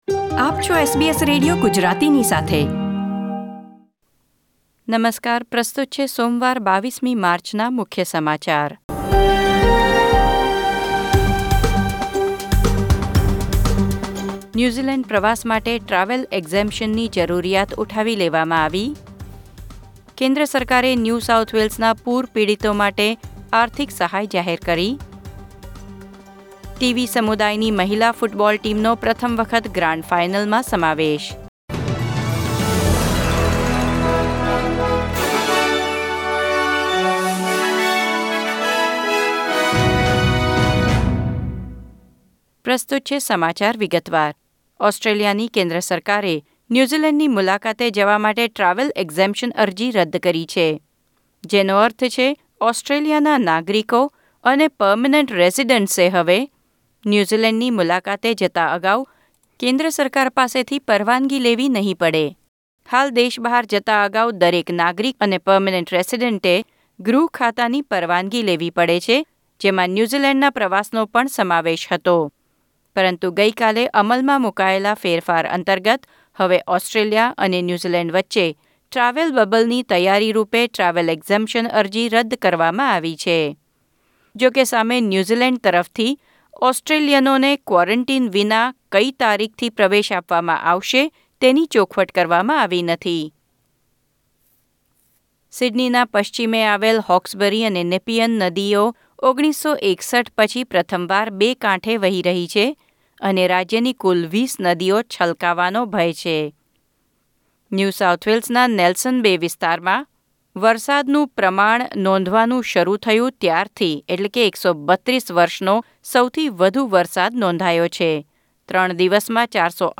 SBS Gujarati News Bulletin 22 March 2021